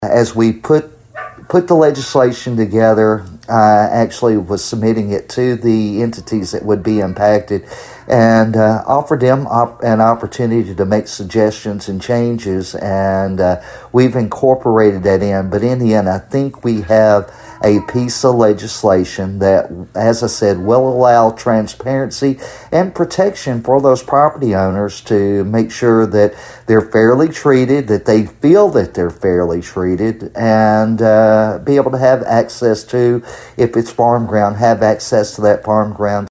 Dossett says input has been sought from groups involved in eminent domain proceedings.